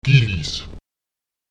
Lautsprecher gilis [Ègilis] die Kinder